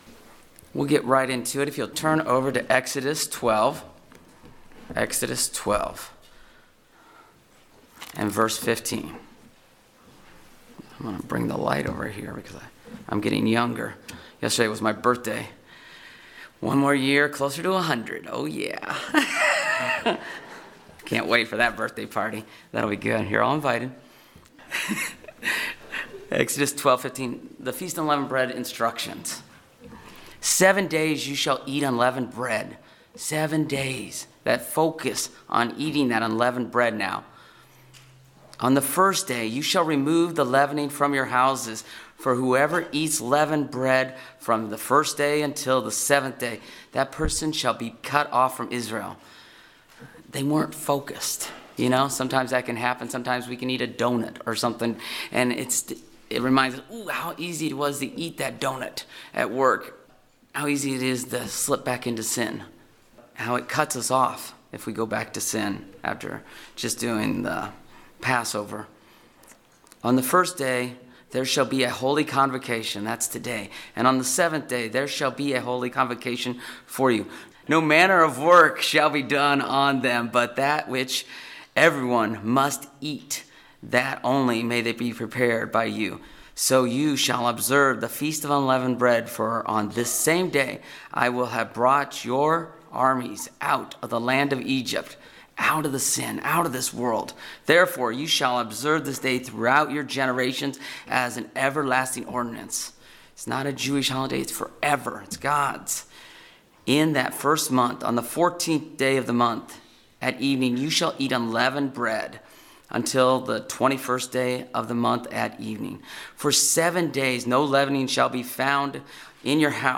Are we focused on staying on God's path more diligently by avoiding and overcoming evil with good? This sermon has four points to staying on God's path.